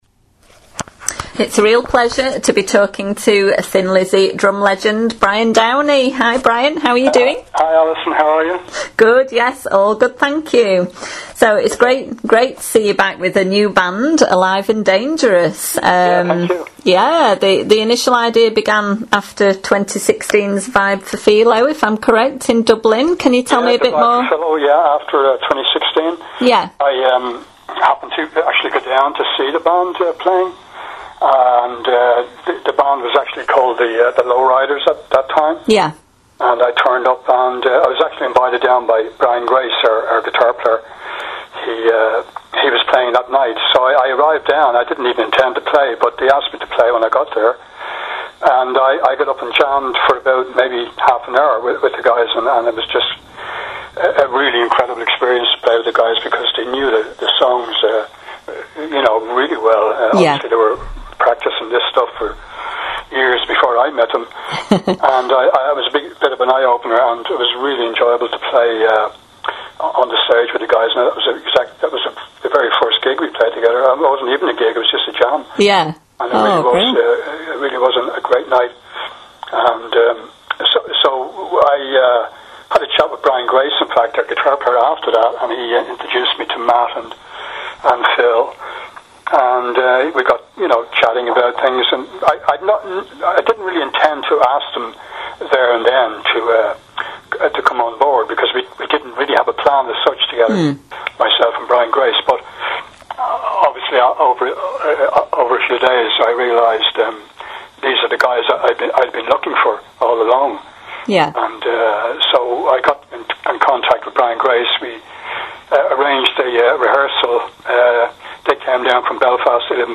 brian-downey-interview-2018.mp3